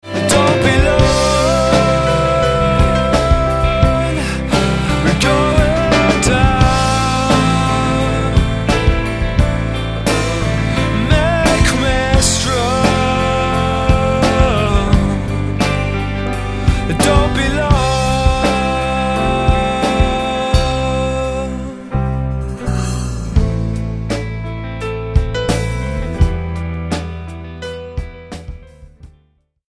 STYLE: Rock
piano driven